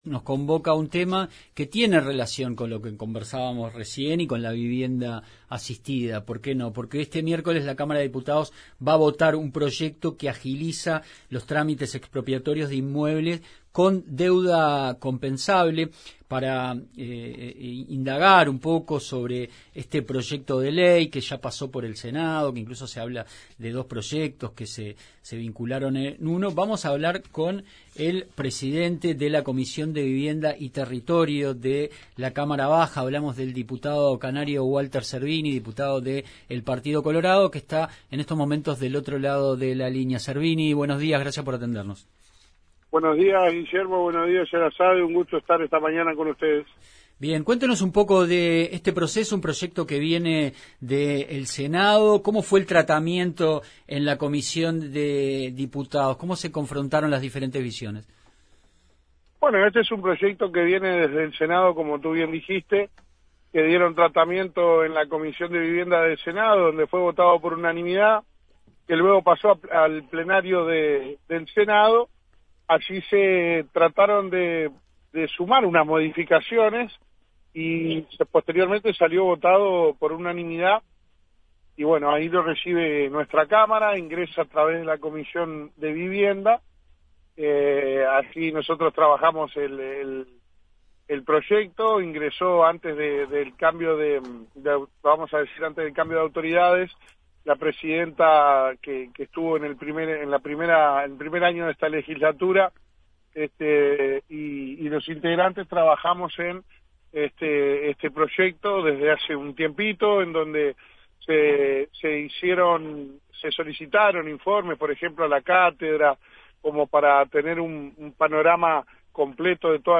Entrevista a Walter Cervini, diputado colorado por Canelones y el presidente de la Comisión de Vivienda y Territorio